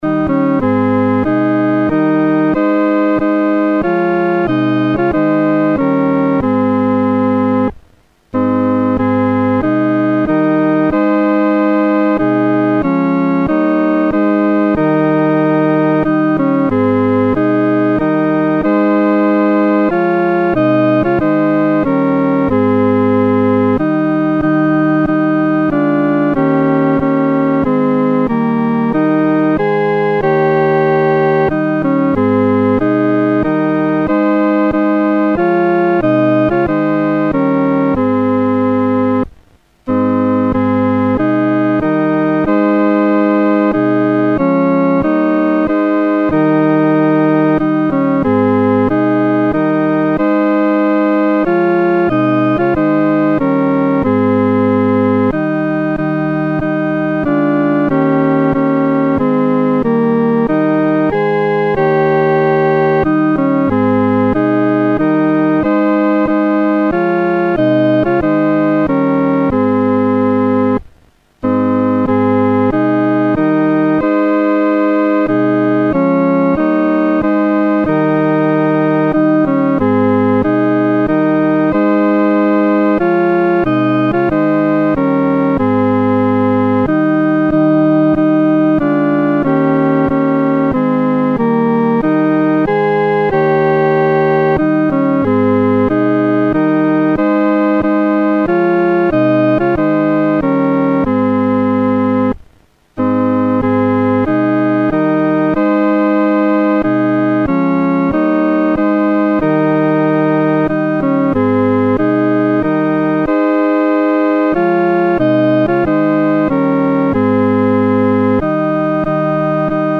独奏（第二声）
来敬拜荣耀王-独奏（第二声）.mp3